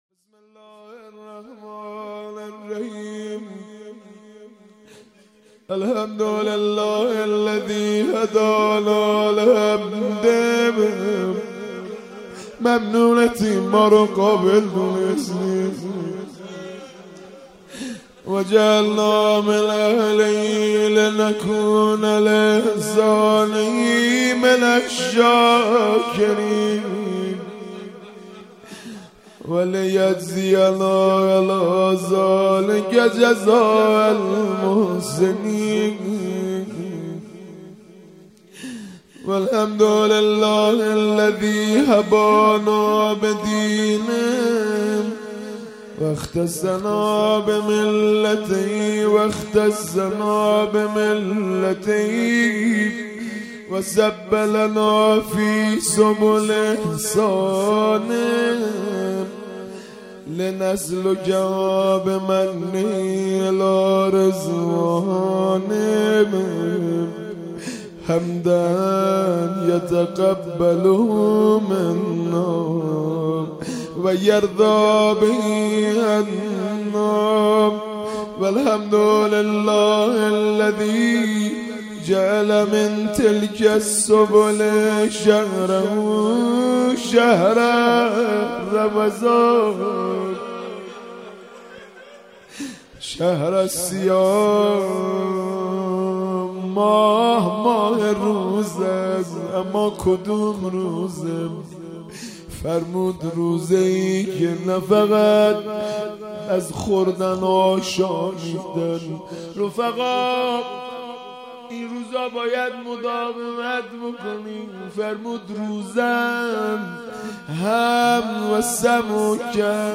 صوت/ مناجات خوانی زیبای
در اولین شب از مناجات خوانی شب های ماه مبارک رمضان در هیئت ثارالله زنجان.